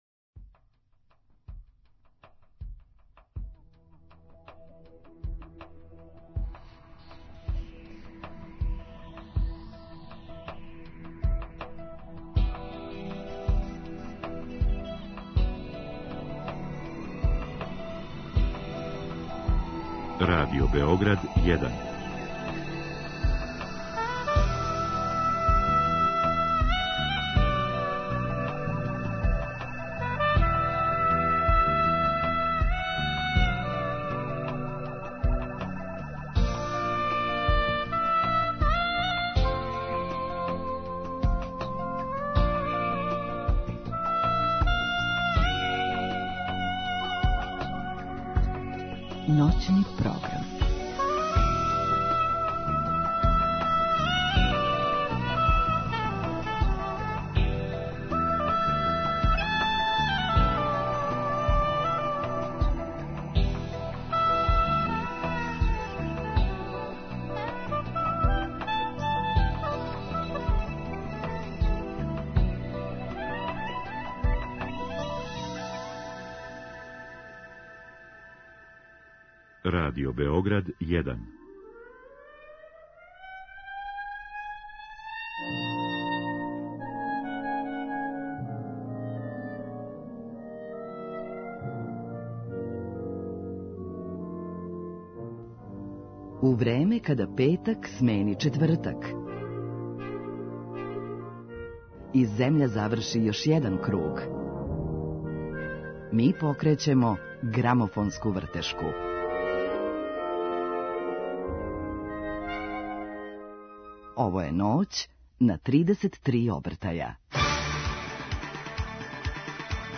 Гости: група Лифт